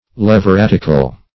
\Lev`i*rat"ic*al\ (-r[a^]t"[i^]*kal), a. [L. levir a husband's